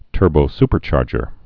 (tûrbō-spər-chärjər)